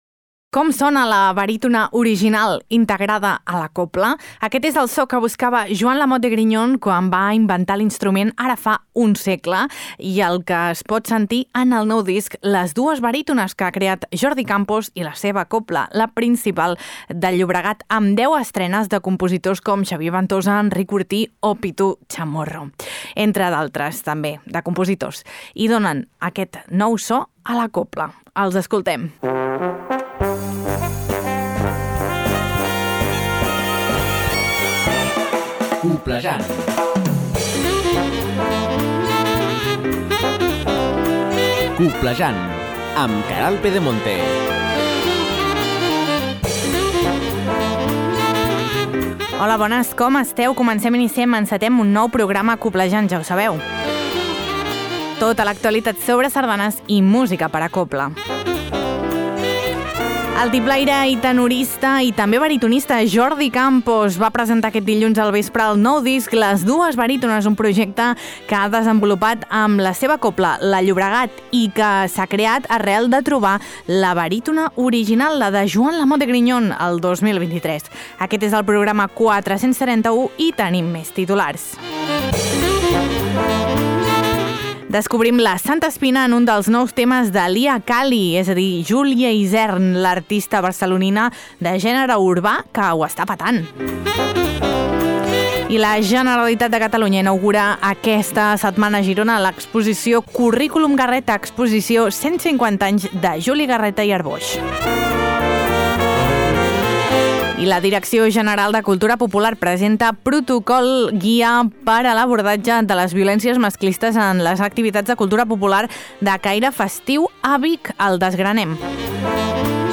Tot això i molt més a Coblejant, un magazín de Ràdio Calella Televisió amb l’Agrupació Sardanista de Calella i en coproducció amb La Xarxa de Comunicació Local que s’emet per 75 emissores a tots els Països Catalans. T’informa de tot allò que és notícia al món immens de la sardana i la cobla.